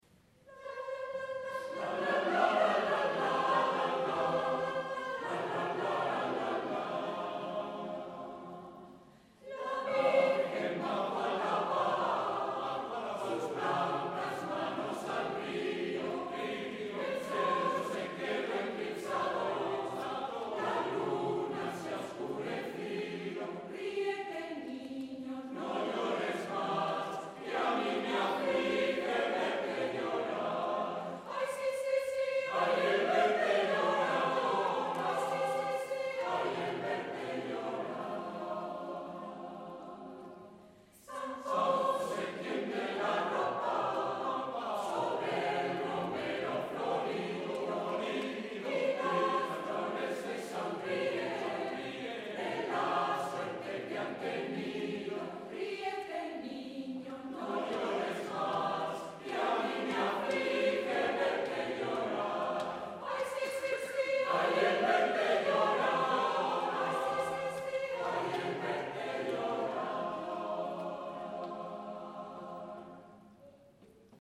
en concierto